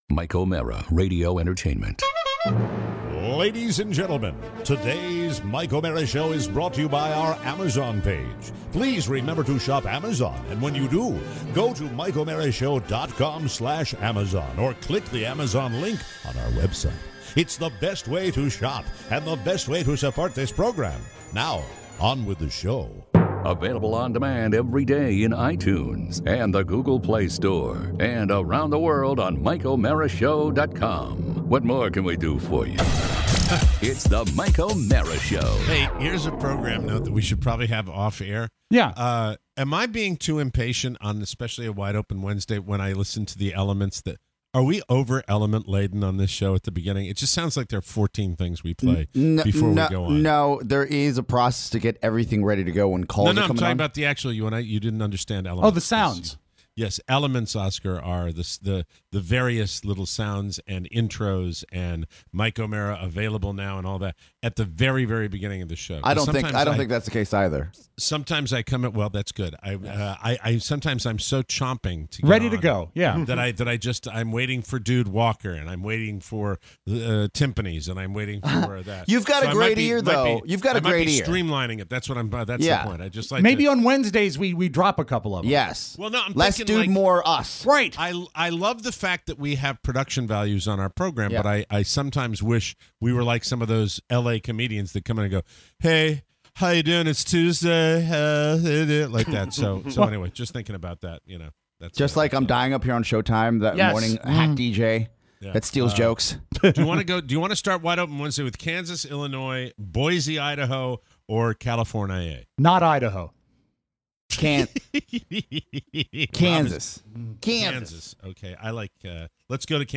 Your calls on the helpline! A live report from Graceland! Thoughts on the Glass Slipper… and the phrases of a four year old.